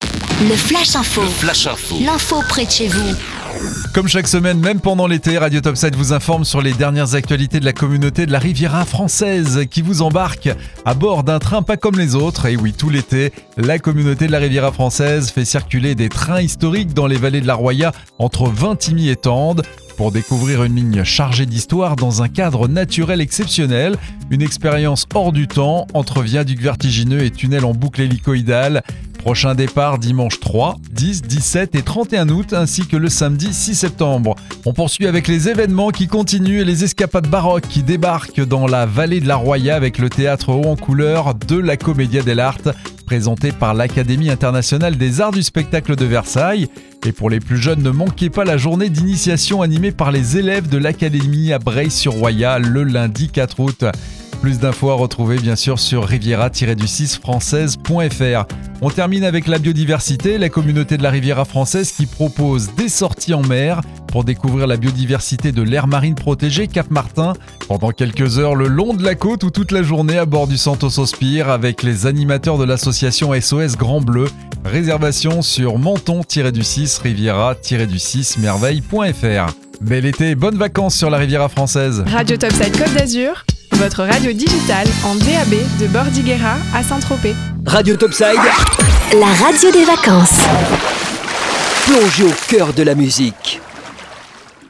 C.A.R.F ACTU - FLASH INFO SEMAINE 31